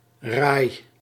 RAI Amsterdam Convention Centre,[1] formerly Amsterdam RAI Exhibition and Convention Centre or simply RAI Amsterdam[1] (Dutch pronunciation: [raːi]